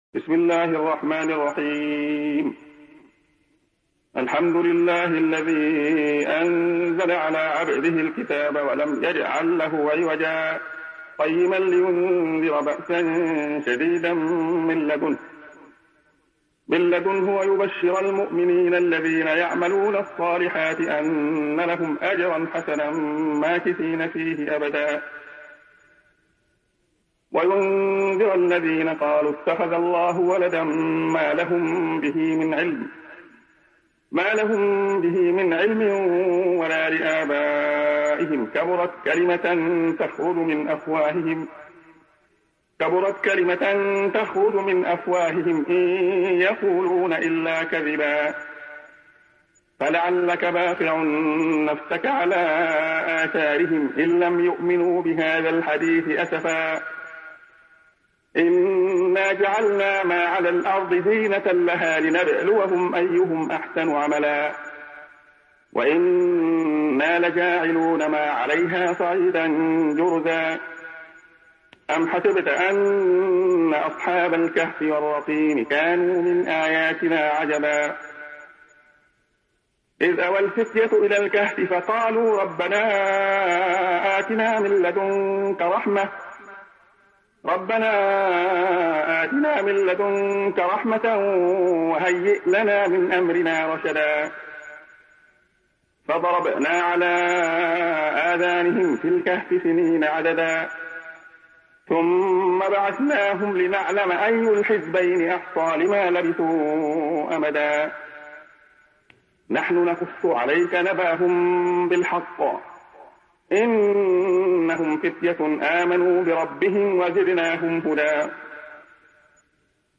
تحميل سورة الكهف mp3 بصوت عبد الله خياط برواية حفص عن عاصم, تحميل استماع القرآن الكريم على الجوال mp3 كاملا بروابط مباشرة وسريعة